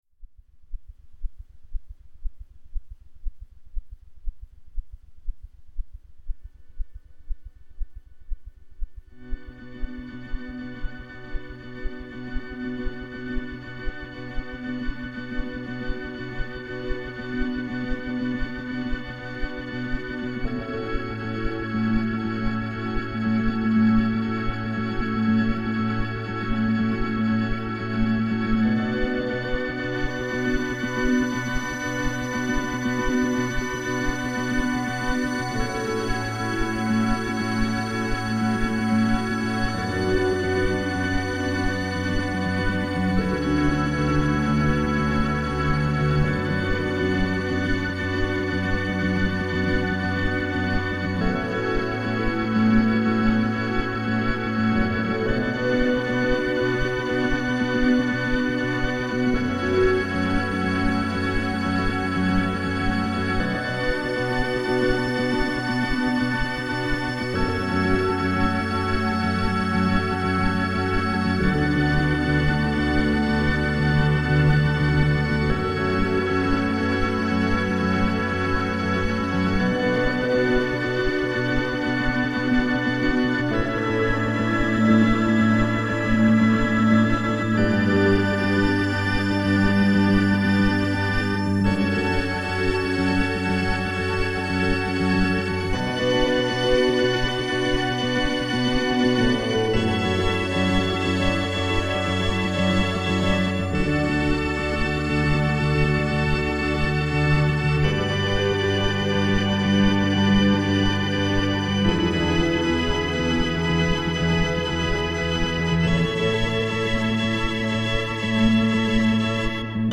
mix. Rather special overdrive behaviour (Dirty!), and (IMO) pleasant way of
Demo 2 ( This is something I recorded a few years ago. I don't remember all the details. But it was a Split on the OB-8, bass sound on left hand and organ sound on right hand, and the whole thing was processed with the Variable Slope Phaser / Filter to get that "pulsating" sound. Lots of delay and reverb as well, obviously.):